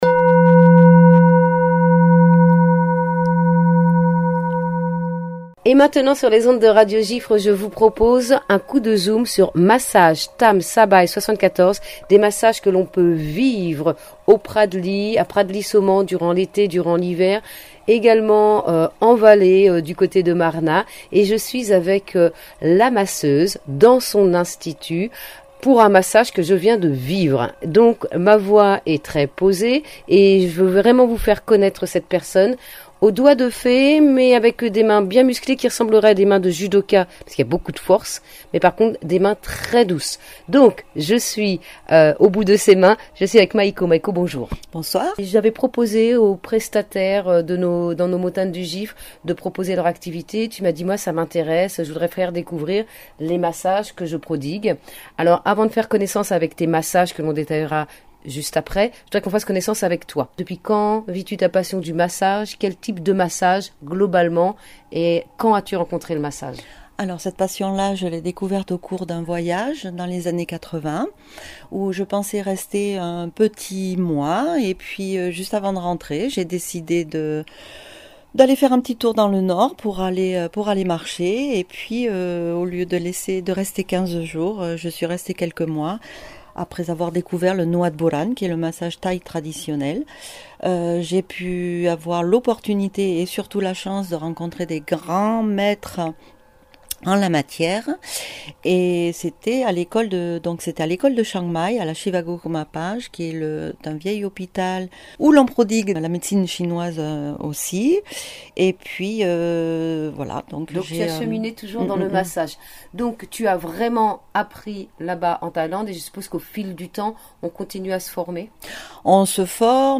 interview Radio Giffre Haute-Savoie